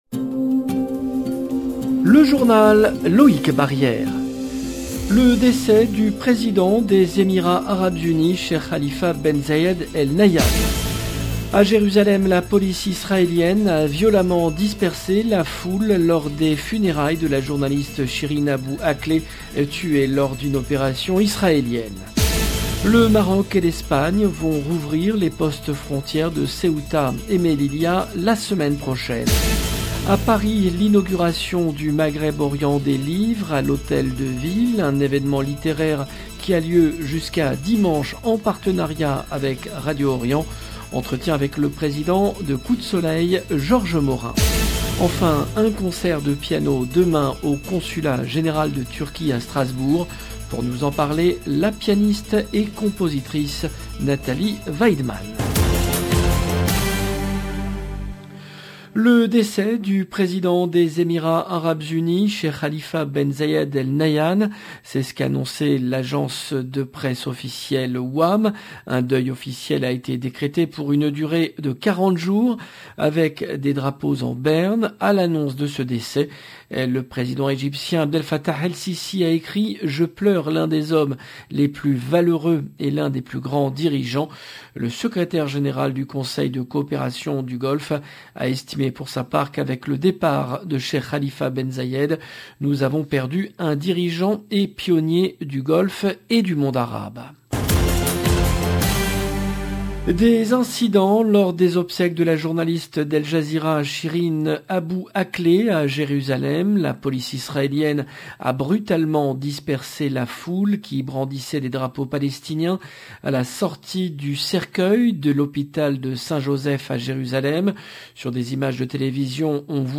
LE JOURNAL DU SOIR EN LANGUE FRANCAISE DU 13/05/22